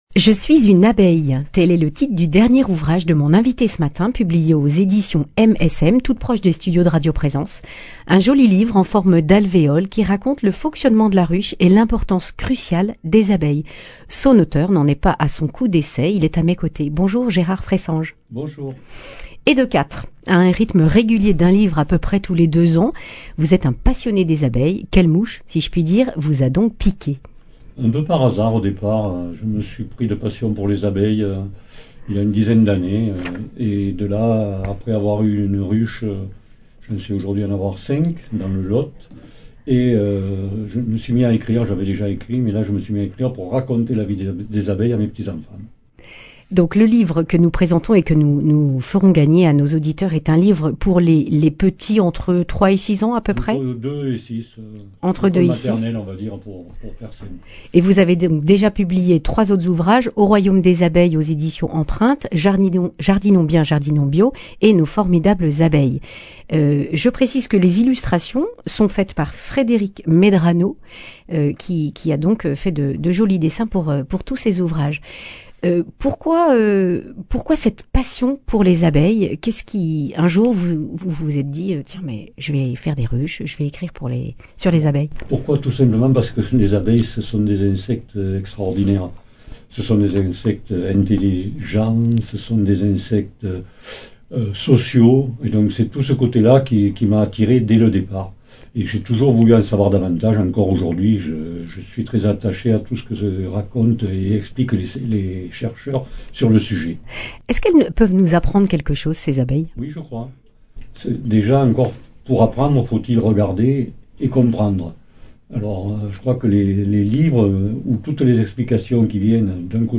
vendredi 18 février 2022 Le grand entretien Durée 11 min